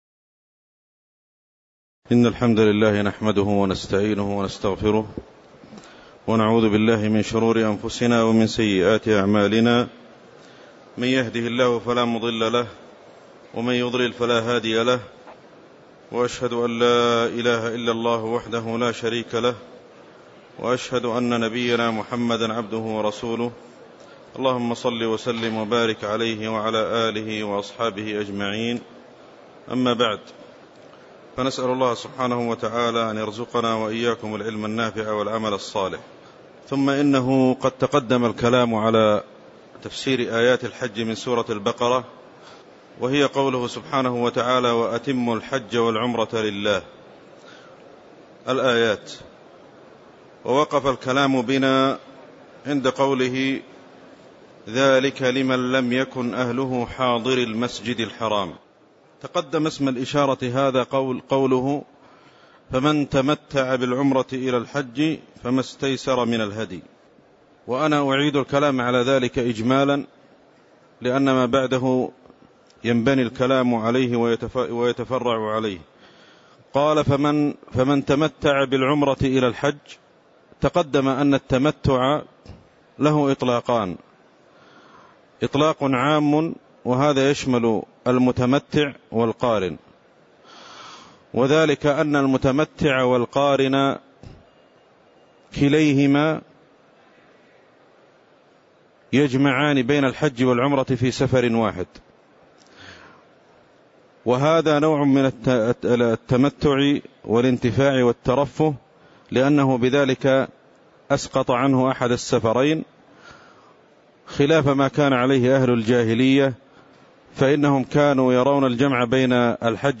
تاريخ النشر ٣ ذو الحجة ١٤٣٩ هـ المكان: المسجد النبوي الشيخ